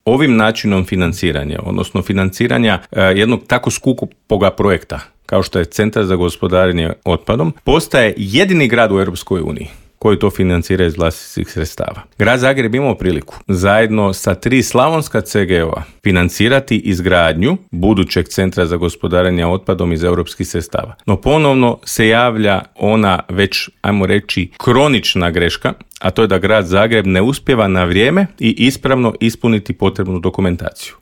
U metropoli po drugi mandat ide aktualni gradonačelnik Tomislav Tomašević, a gradonačelničkog kandidata HDZ-a Mislava Hermana ugostili smo u Intervjuu Media servisa.